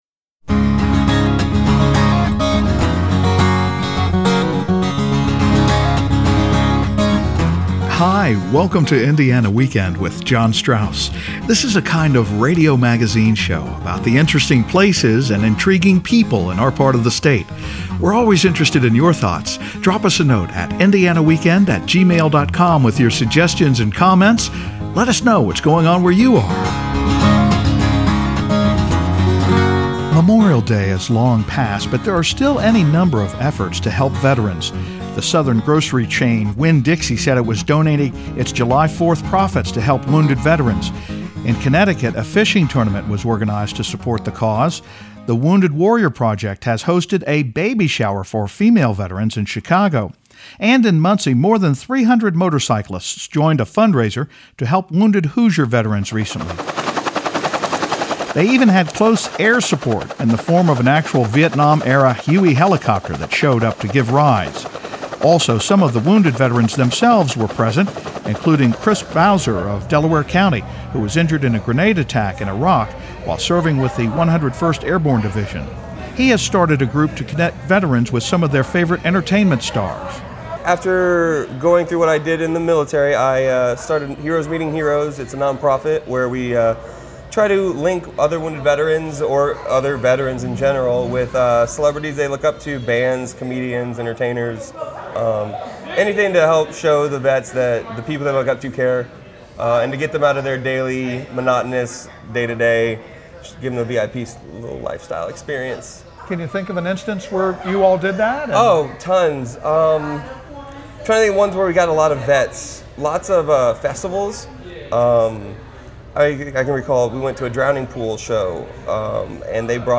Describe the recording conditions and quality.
That includes Muncie, where more than 300 motorcycle riders supported a fund-raiser for wounded Hoosier veterans. We meet two vets at the event, one who nearly lost his legs in a grenade attack, to talk about their experiences.